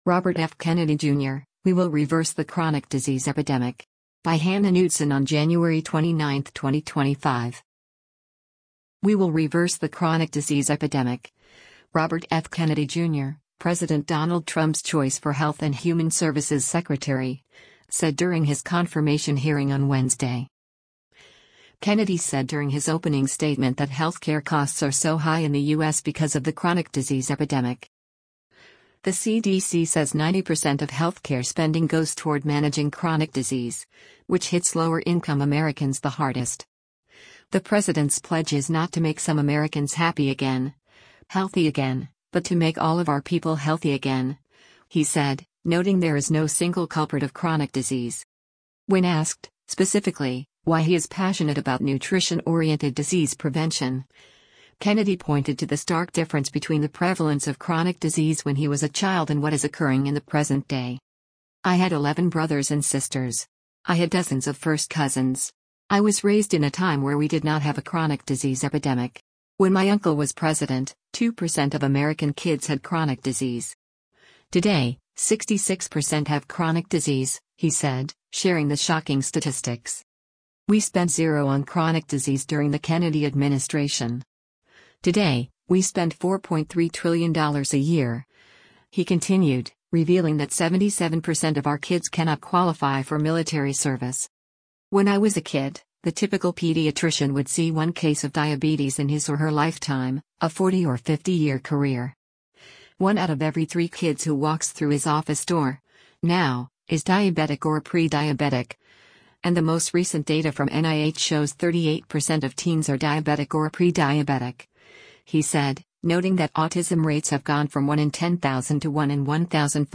“We will reverse the chronic disease epidemic,” Robert F. Kennedy Jr., President Donald Trump’s choice for Health and Human Services Secretary, said during his confirmation hearing on Wednesday.